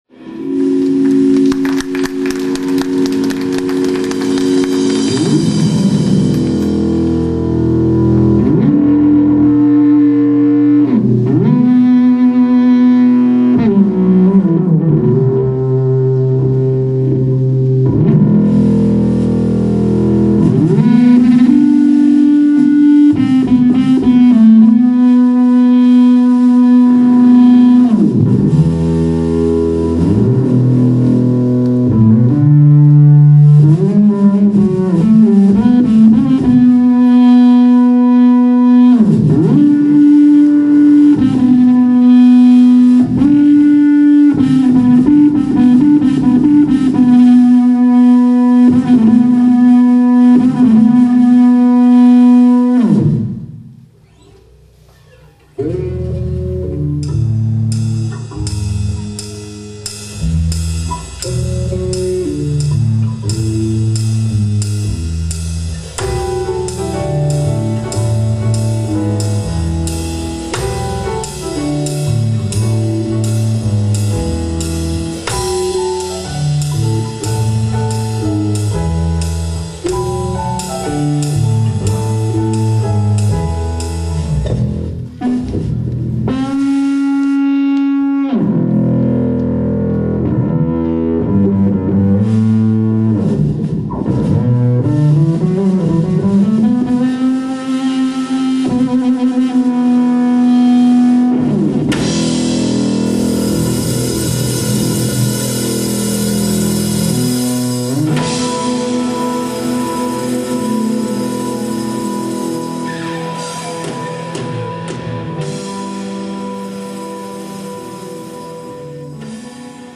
このエフェクタをBB-NE2を使ったライブの音。客席からのワンポイントマイクでの録音ですが、音色が音色なので、どんなもんかわかると思います。ちなみに歪みです。
いわゆるデジタルマルチエフェクタです。